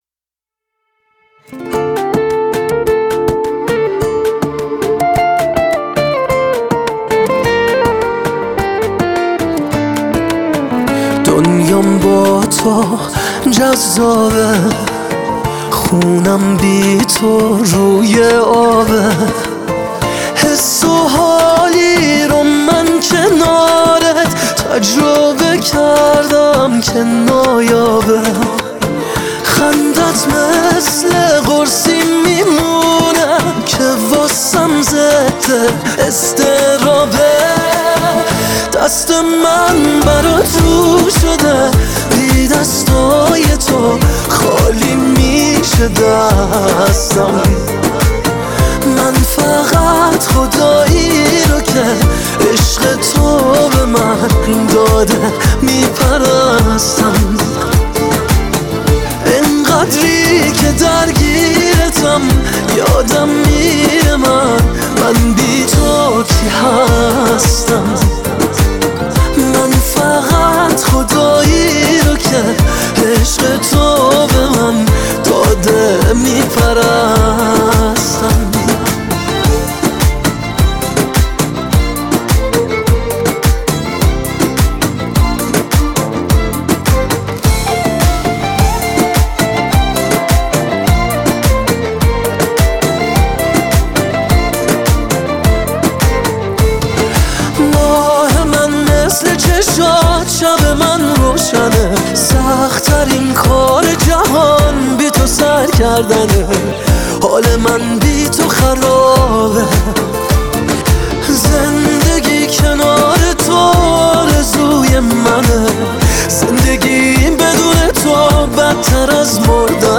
دانلود آهنگ پاپ ایرانی